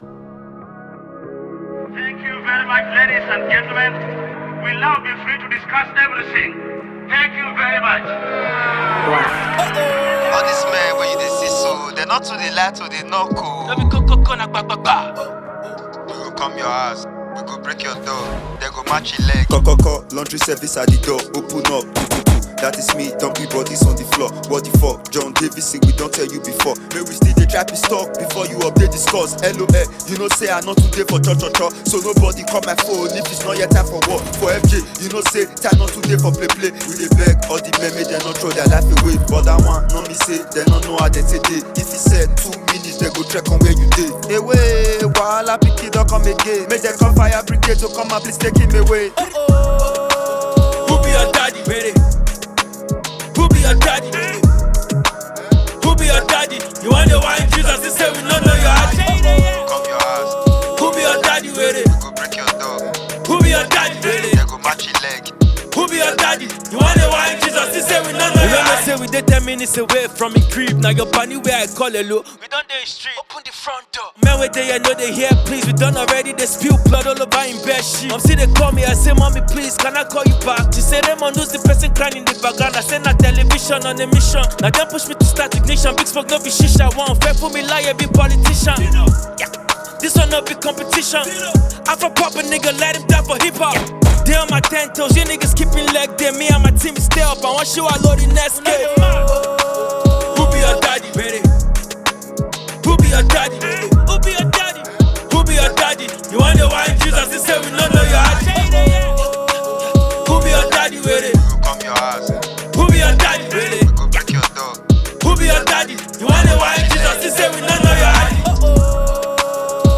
a veteran Nigerian rapper.